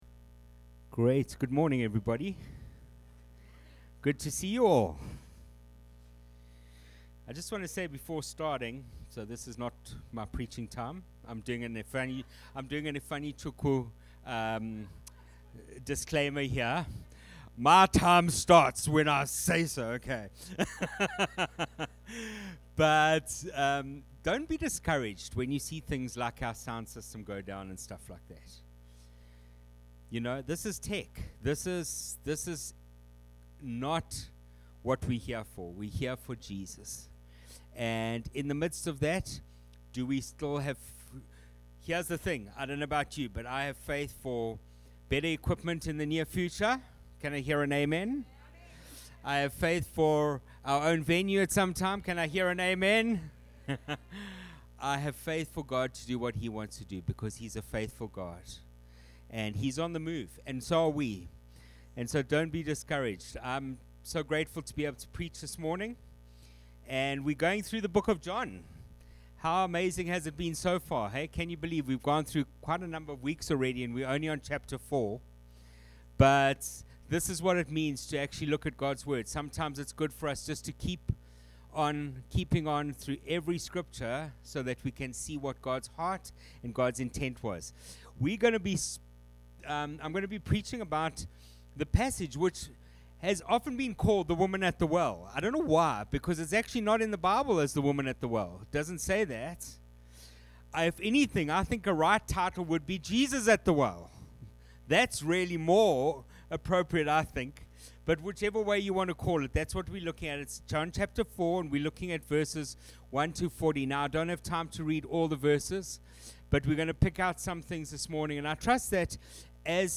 Sermons | Explore Life Church